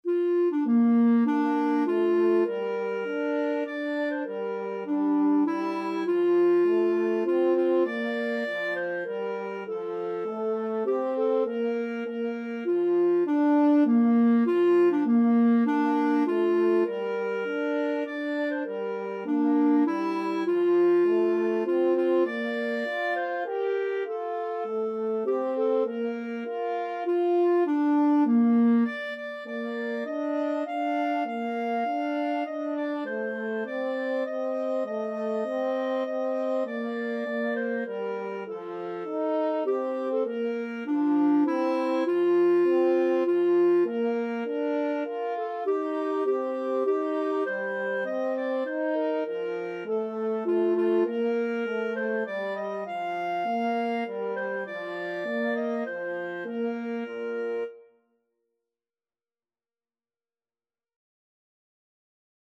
Free Sheet music for Clarinet-French Horn Duet
ClarinetFrench Horn
3/4 (View more 3/4 Music)
Bb major (Sounding Pitch) (View more Bb major Music for Clarinet-French Horn Duet )
Traditional (View more Traditional Clarinet-French Horn Duet Music)